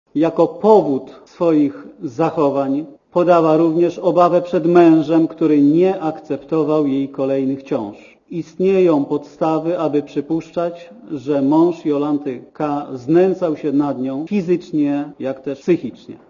Wypowiedź rzecznika prokuratury (64Kb)